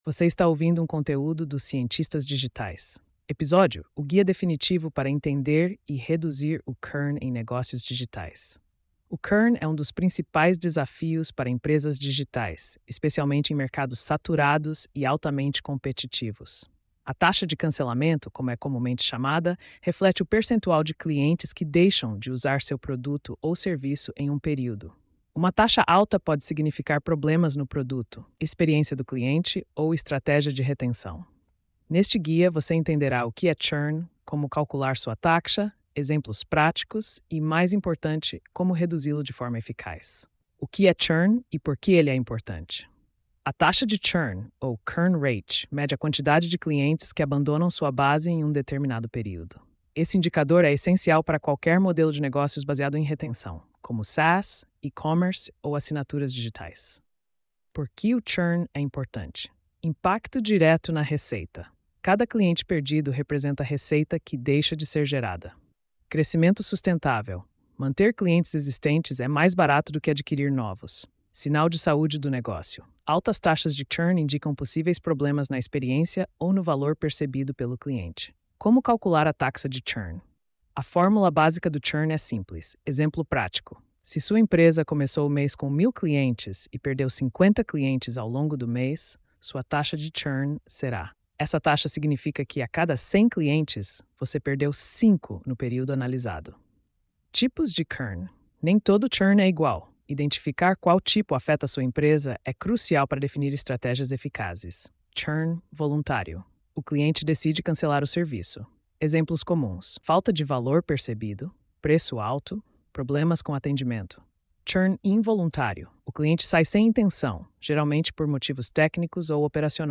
post-2605-tts.mp3